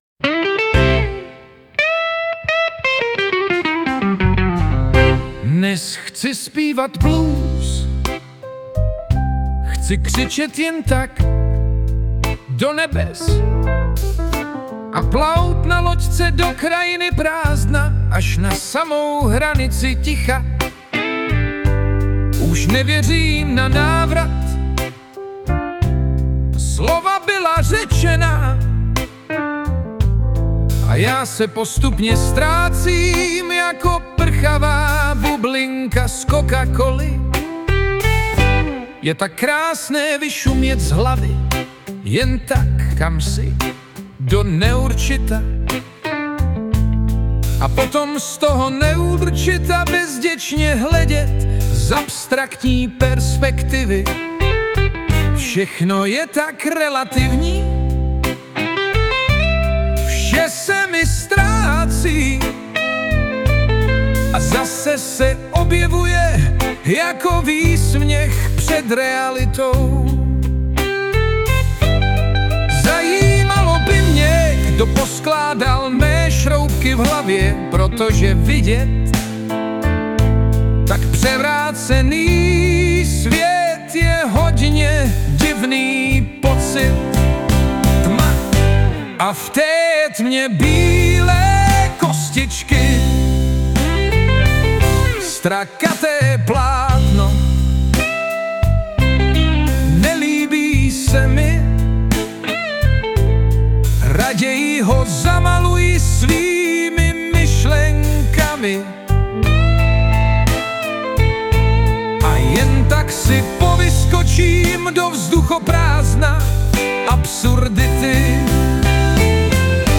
2019 & Hudba, Zpěv a Obrázek: AI
Moc pěkné, blues sice neovládám, ale tohle se mi líbilo.